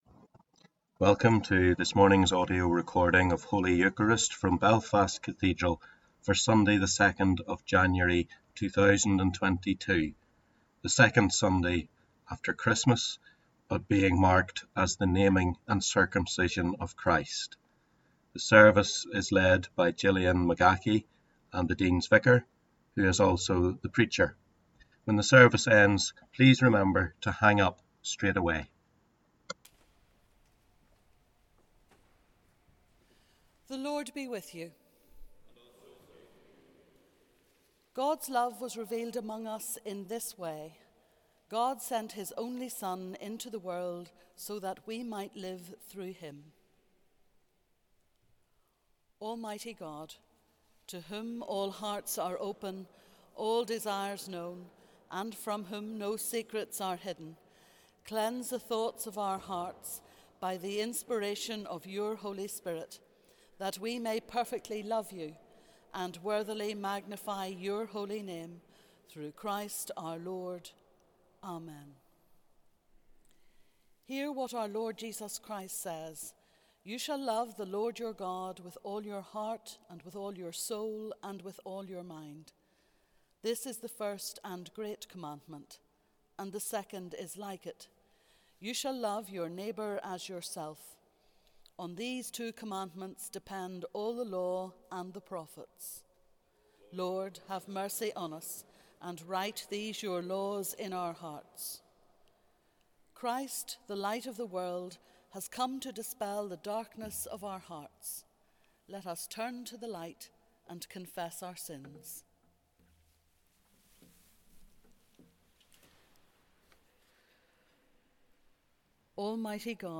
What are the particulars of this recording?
Holy Eucharist on 2 January 2022 marked the Naming and Circumcision of Christ.